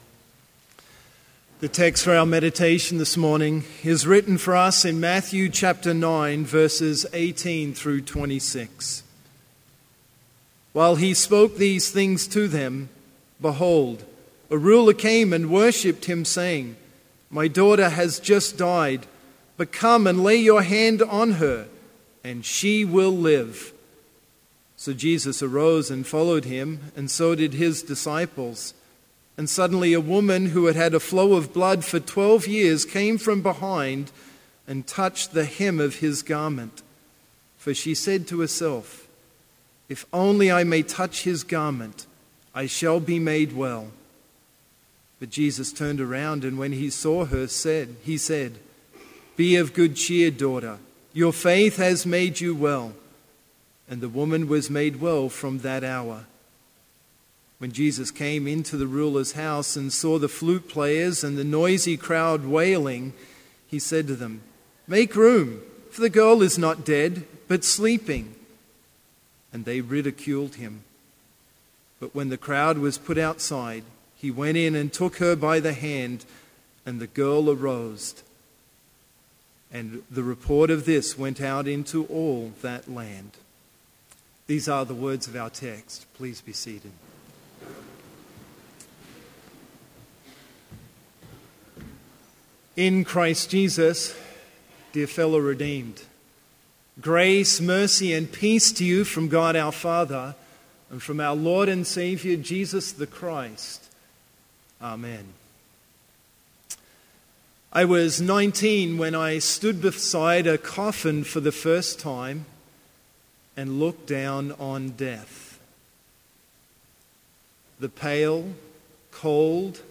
Complete service audio for Chapel - September 21, 2015